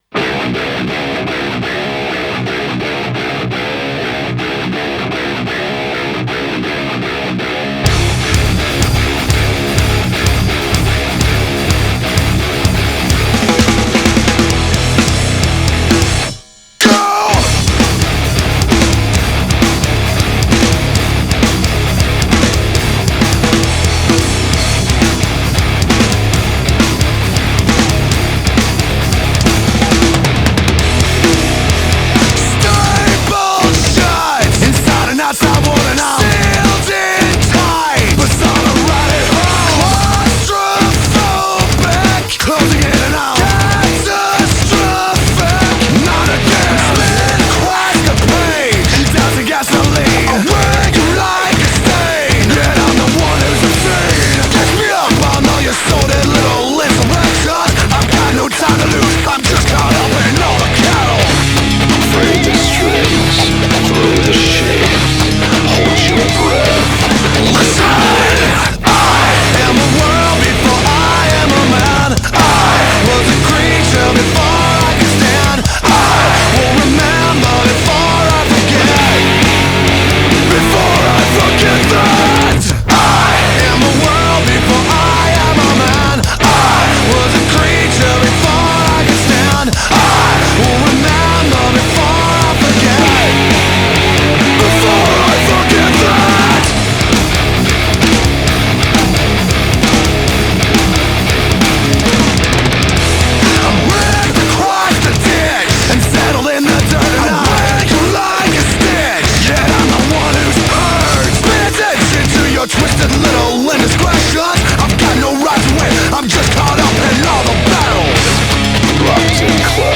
2025-01-03 17:26:40 Gênero: Rock Views